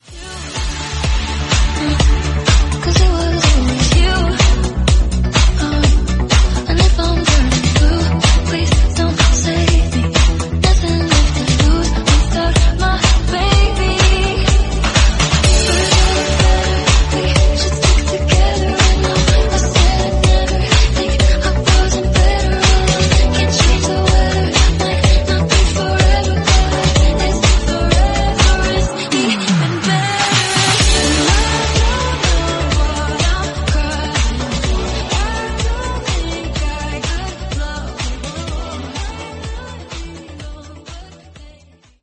Genre: 2000's Version: Clean BPM: 100 Time